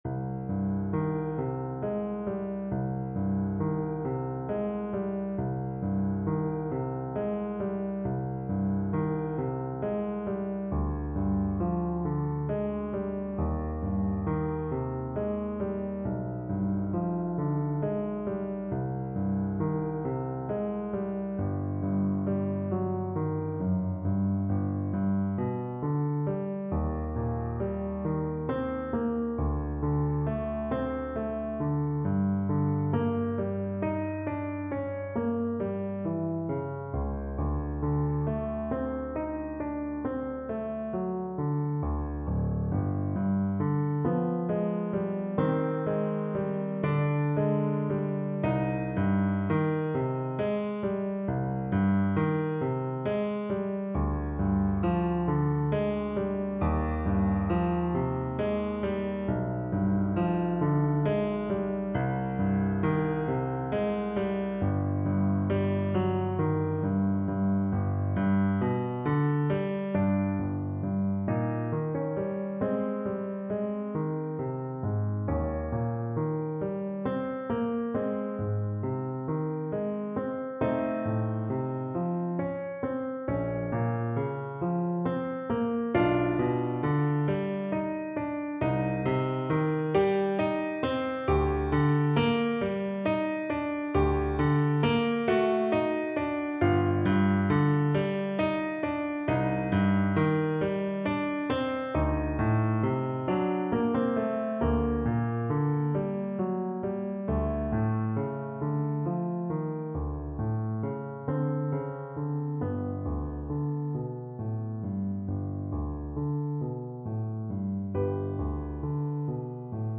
Play (or use space bar on your keyboard) Pause Music Playalong - Piano Accompaniment Playalong Band Accompaniment not yet available transpose reset tempo print settings full screen
Andante = 60
Classical (View more Classical Saxophone Music)
Nocturne_Opus_72_No_1_in_E_Minor_ASAX_kar3.mp3